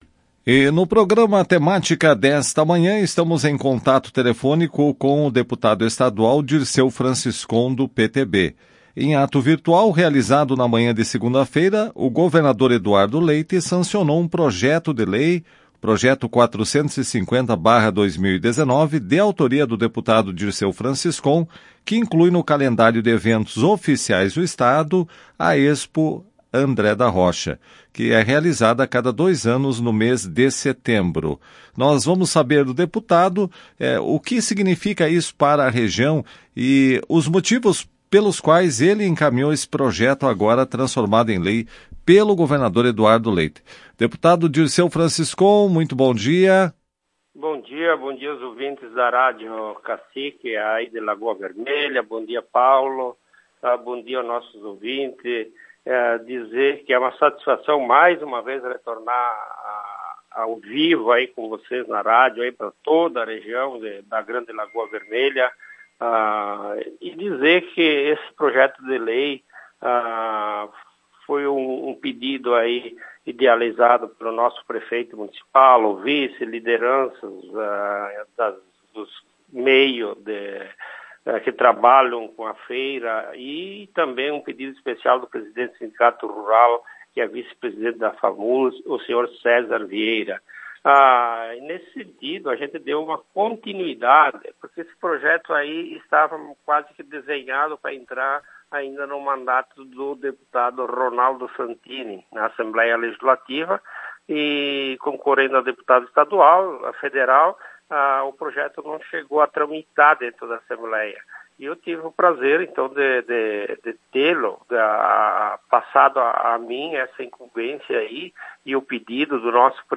Em entrevista à Tua Rádio, o parlamentar falou sobre a importância do evento agrícola que acontece nos anos ímpares, e está na sua 13ª edição.